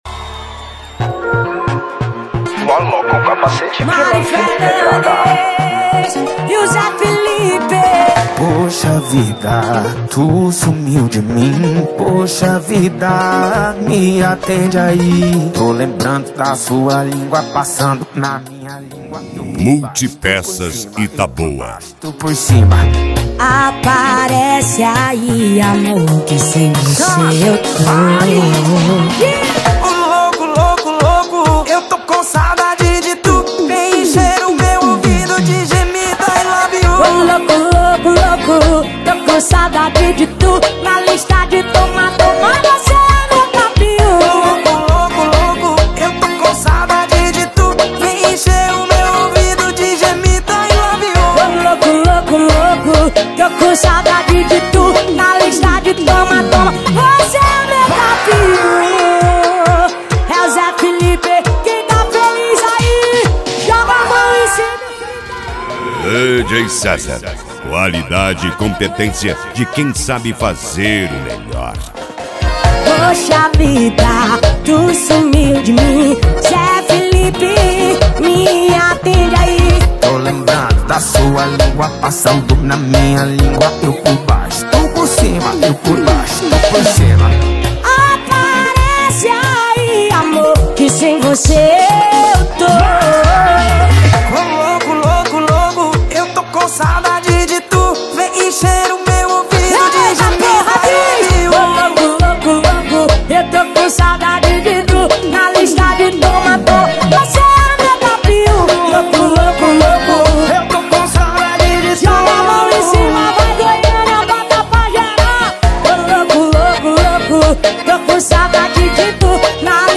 Moda de Viola
Modao
SERTANEJO
Sertanejo Raiz
Sertanejo Universitario